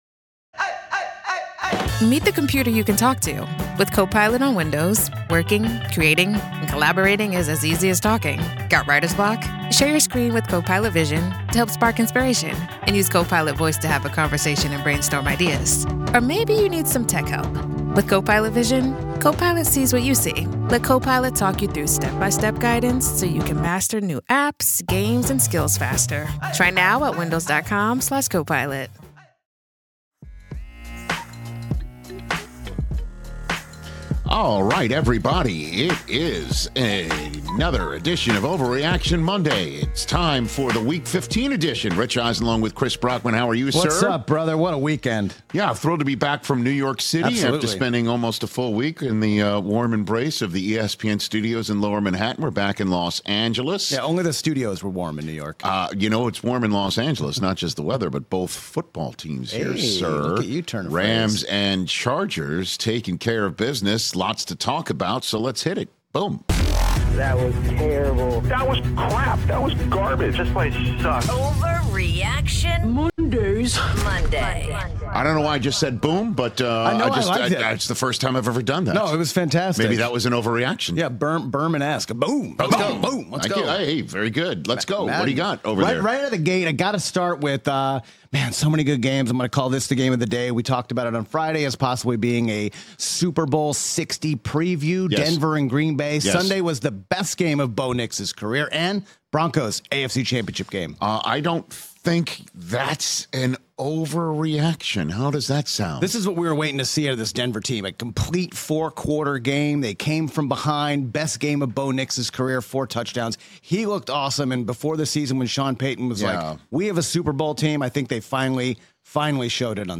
debating the latest in the NFL